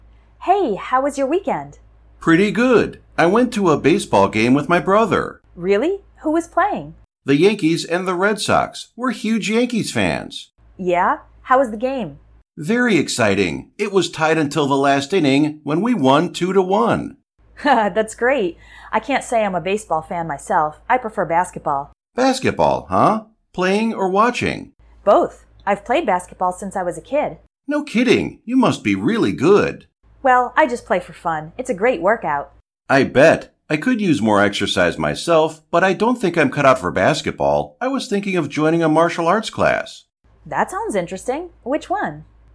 Example English Conversations
Conversation 1: